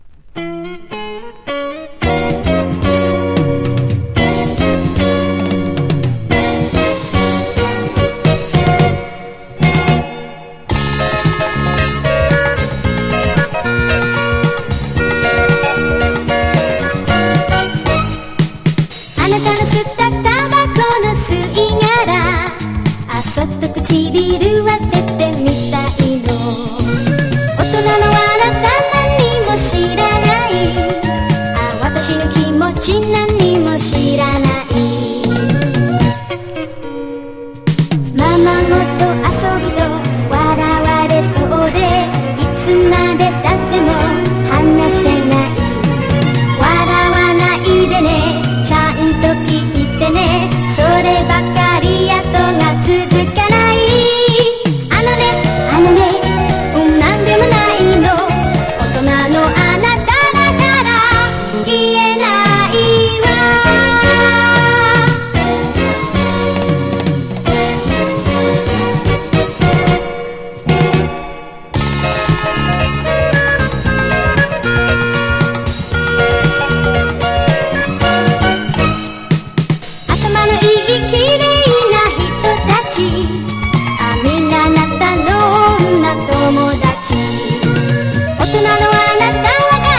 POP# 和モノ / ポピュラー# 70-80’S アイドル